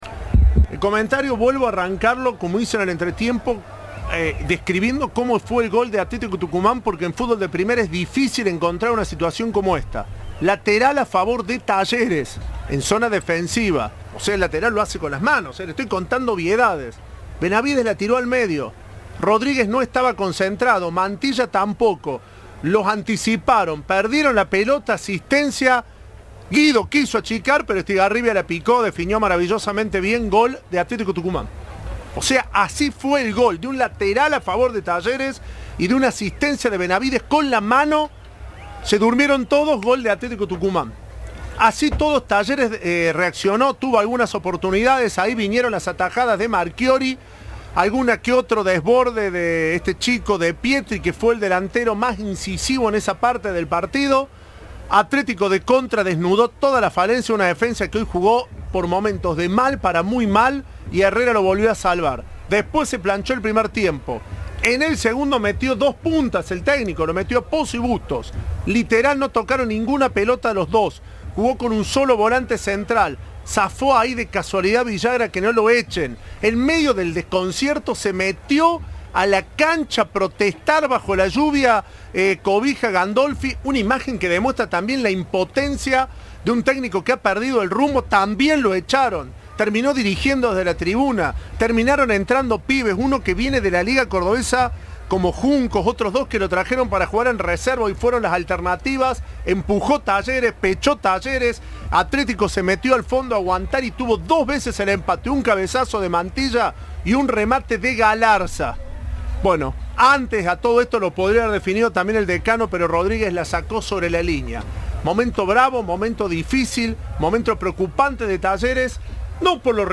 Talleres, Atlético Tucumán, Copa de la Liga, comentario